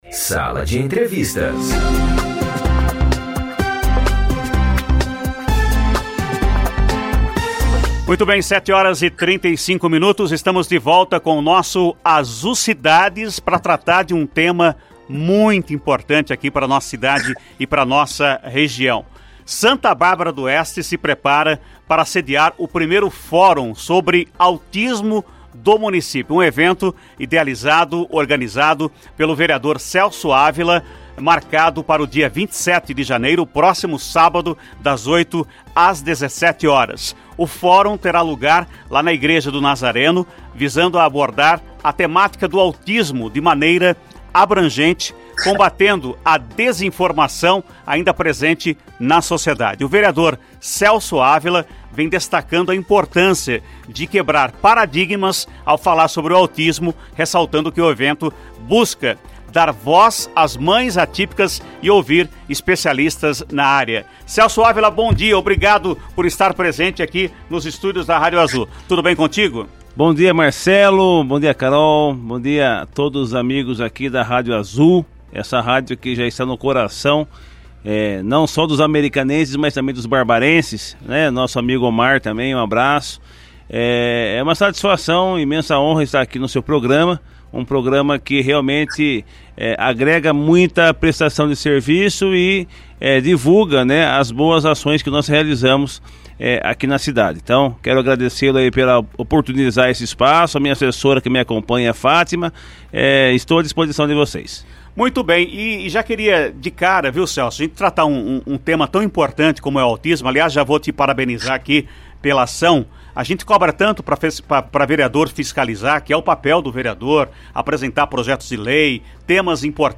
Entrevista com Celso Ávila, vereador em Santa Bárbara d’Oeste
Celso Ávila, vereador em Santa Bárbara d’Oeste, concedeu entrevista exclusiva ao Sala de Entrevistas, do Azul Cidades na manhã desta quinta-feira, 25 de janeiro de 2024.
entrevista-celso-avila.mp3